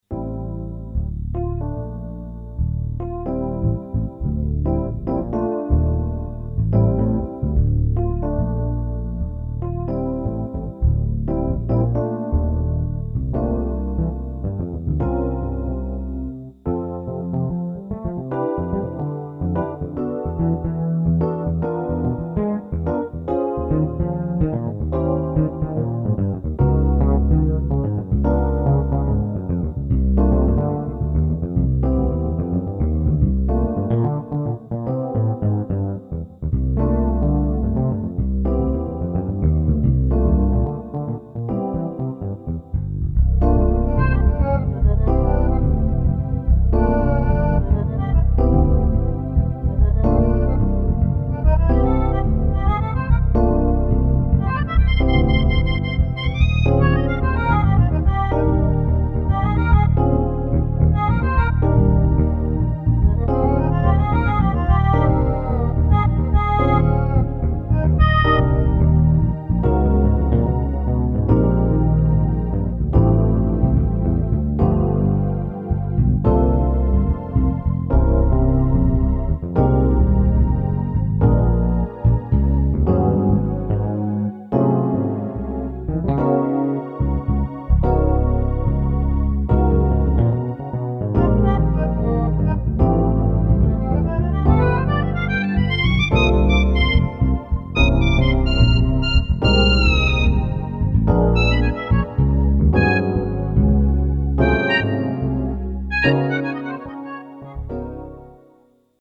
• Жанр: Джаз
Сыграно на Casio-CTK.